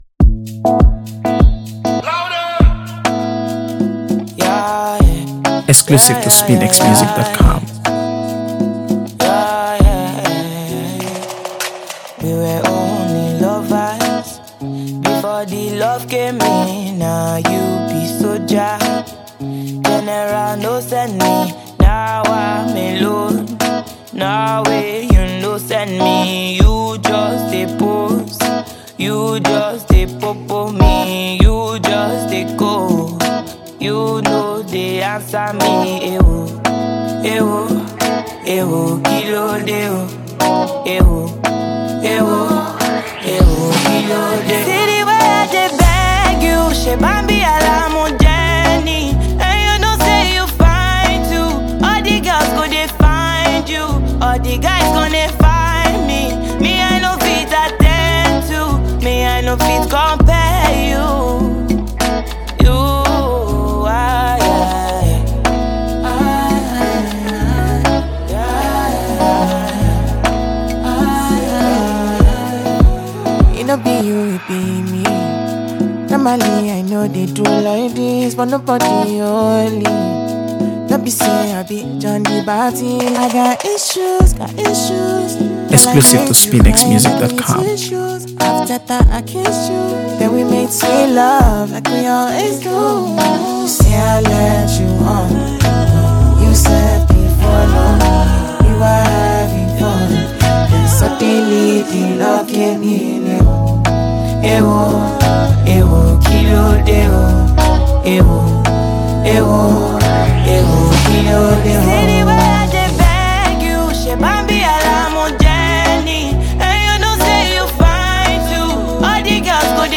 AfroBeats | AfroBeats songs
Nigerian singer-songwriters